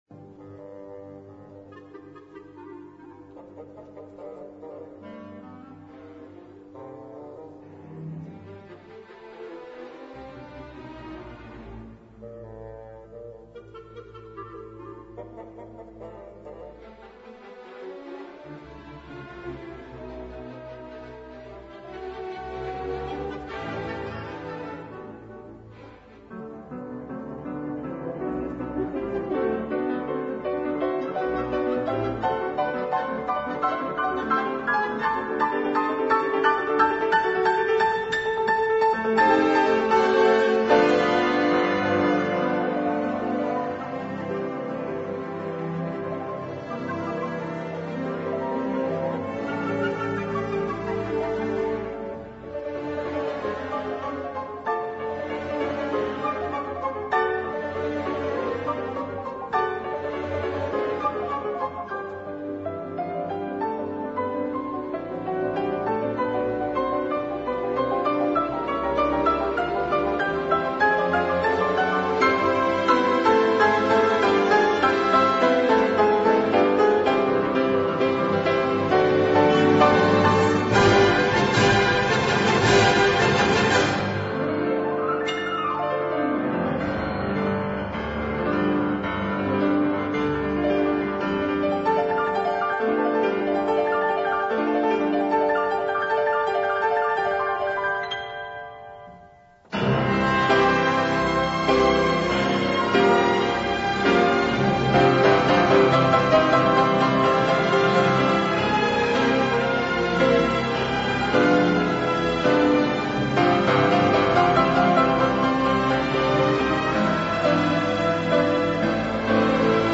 سپس کنسرتو با کودای مجللی به پایان می رسد.